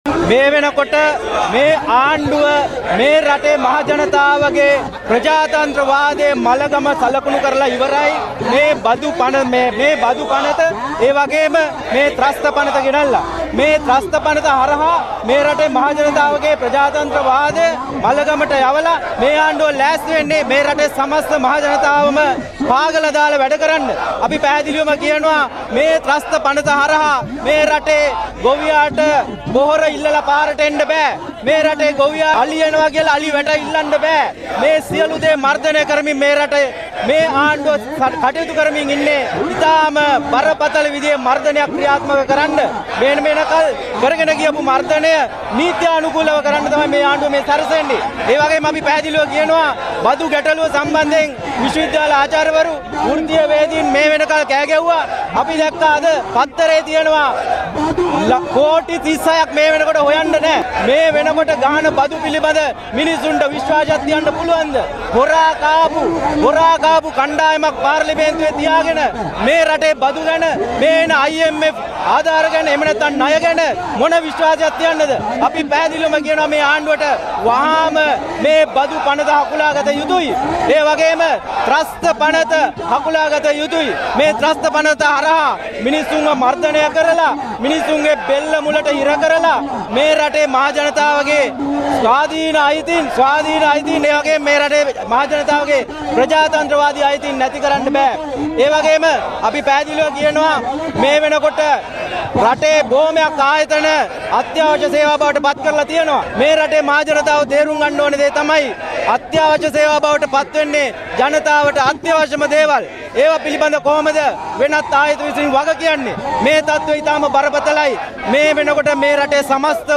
බදු පනතට සහ ප්‍රතිත්‍රස්ත පනතට විරෝධය දක්වමින් අද දින වෘත්තිය සමිති කිහිපයක් කොටුව දුම්රියපළ ඉදිරිපිටදී විරෝධතා ව්‍යාපාරයක් පවත්වනු ලැබුවා.